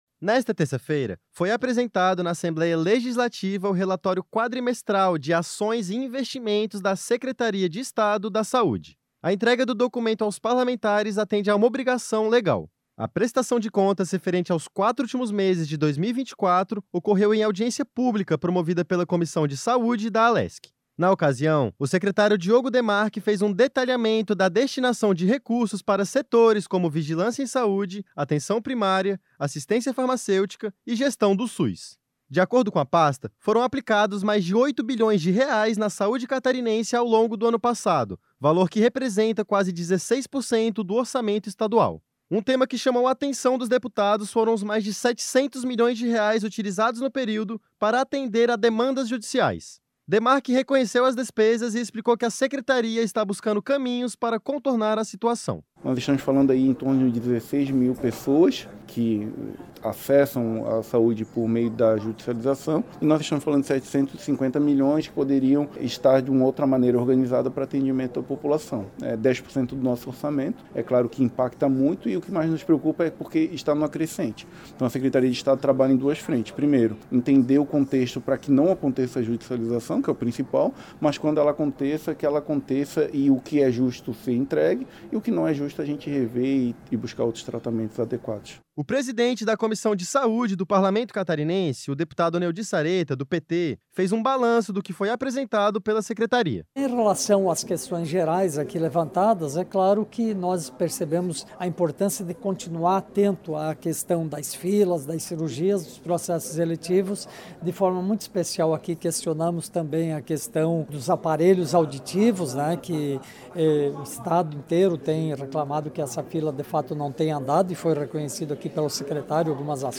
A prestação de contas referente aos quatro últimos meses de 2024 ocorreu em audiência pública promovida pela Comissão de Saúde da Alesc.
Entrevista com:
- Diogo Demarchi, secretário de Estado da Saúde de Santa Catarina;
- deputado Neodi Saretta (PT), presidente da Comissão de Saúde da Assembleia Legislativa;
- deputado Dr. Vicente Caropreso (PSDB), vice-presidente da Comissão de Saúde da Assembleia Legislativa.